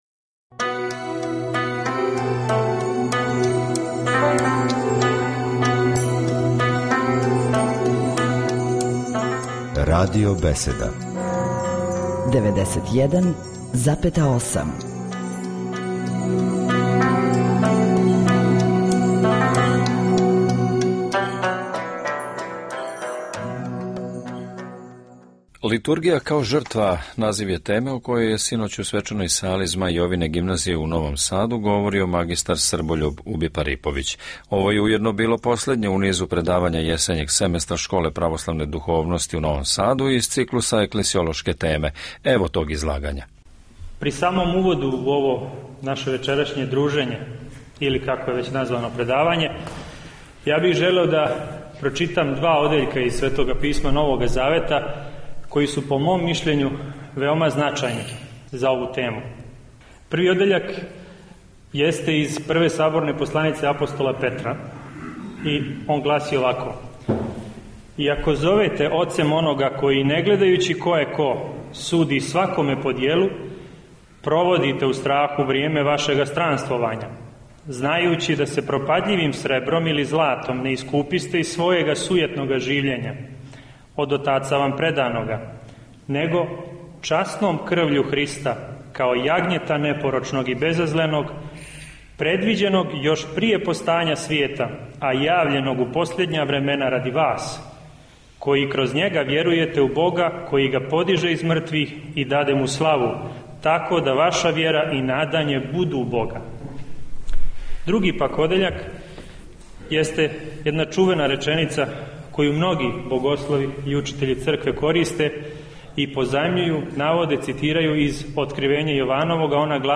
Звучни запис предавања које је у недељу